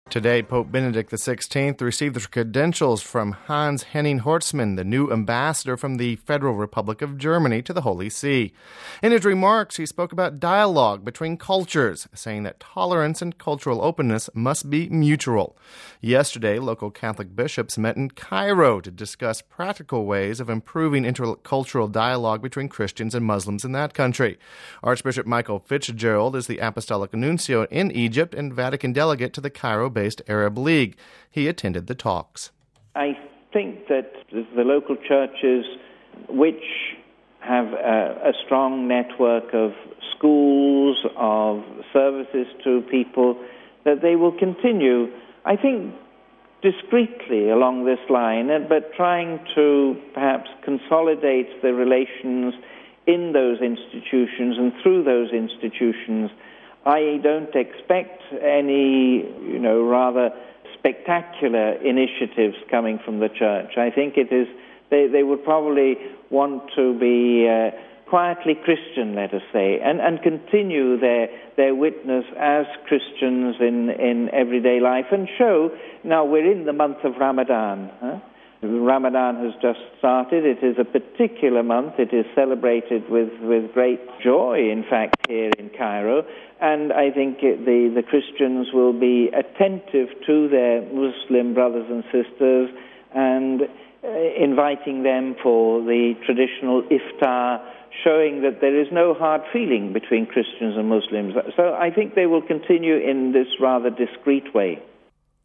Home Archivio 2006-09-28 18:40:00 Interfaith Dialogue (28 Sept 06 - RV) Catholic officials in Egypt have met to discuss the fate of interfaith dialogue. Apostolic Nuncio Archbishop Michael Fitzgerald spoke to Vatican Radio...